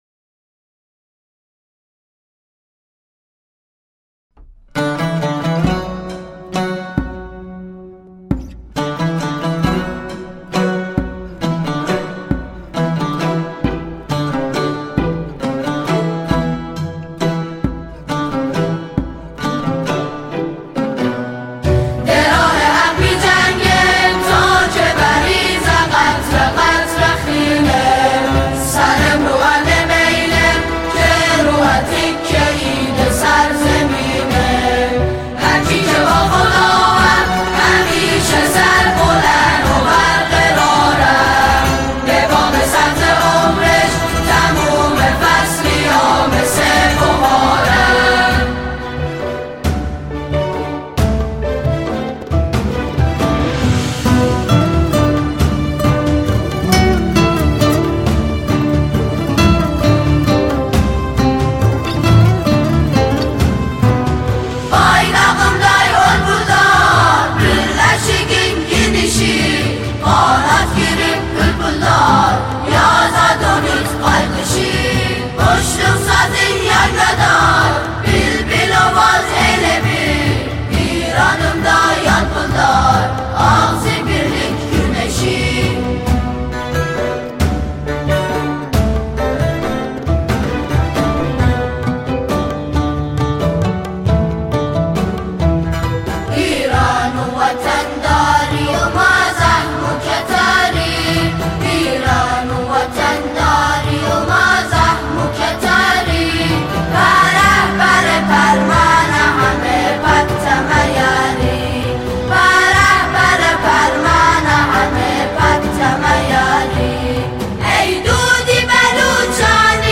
برگرفته از موسیقی فولکلور و زبان اقوام مختلف ایران زمین